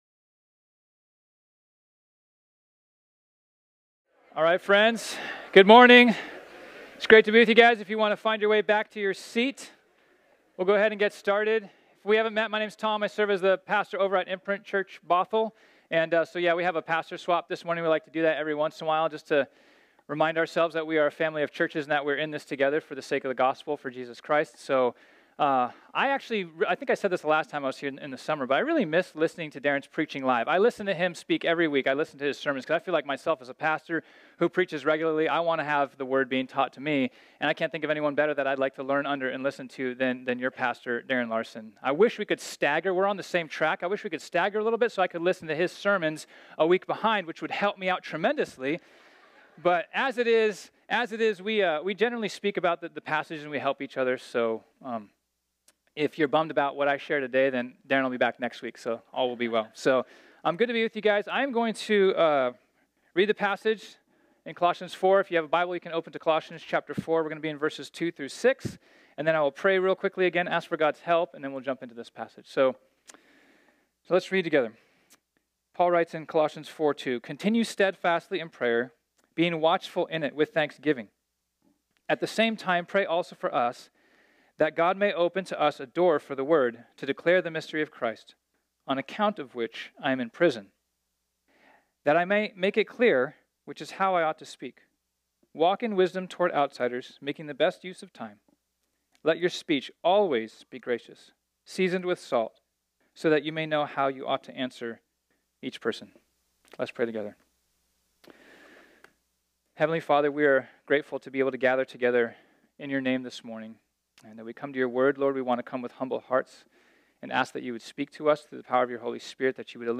This sermon was originally preached on Sunday, November 25, 2018.